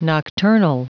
Prononciation du mot nocturnal en anglais (fichier audio)
Prononciation du mot : nocturnal